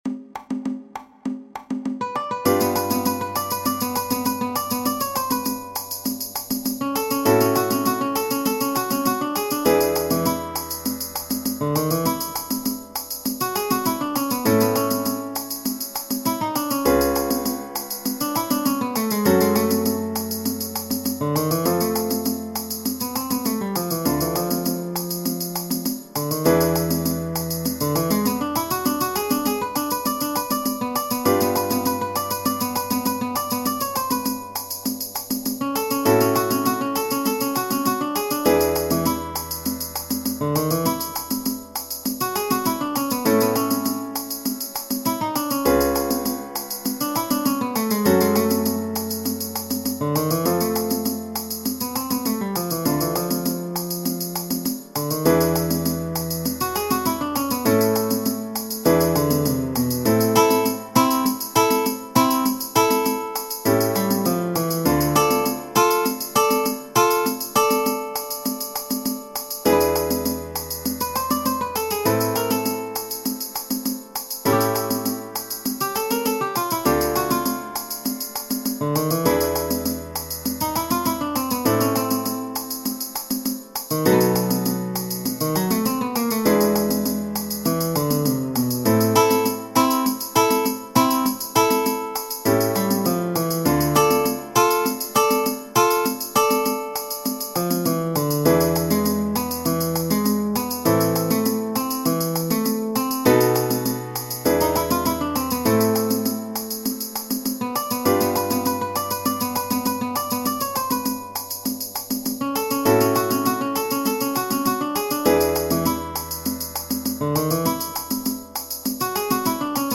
a ritmo di samba